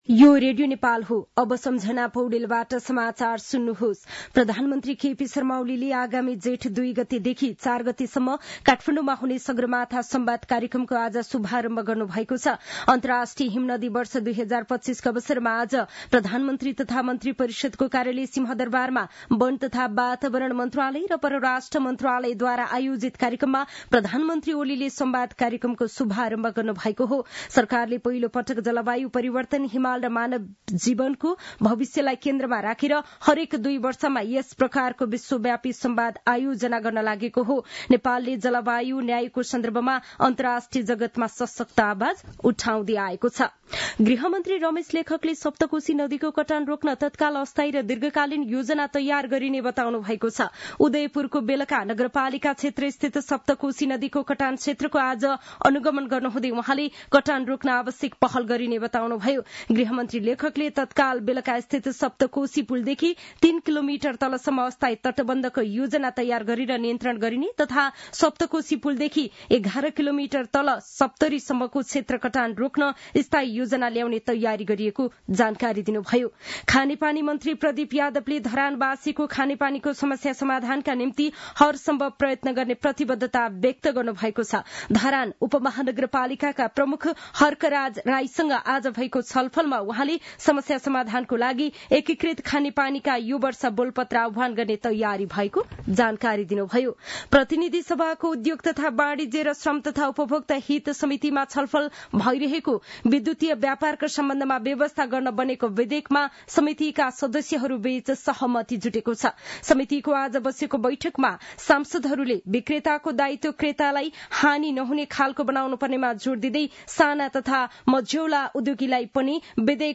साँझ ५ बजेको नेपाली समाचार : ९ माघ , २०८१